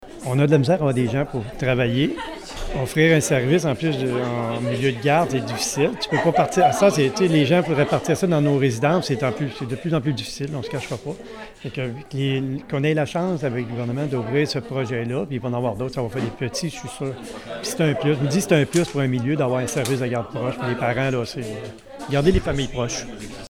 Dans les petites localités rurales, avoir un service de garde peut favoriser l’établissement de familles comme l’a souligné le préfet de la MRC de Bécancour, monsieur Mario Lyonnais.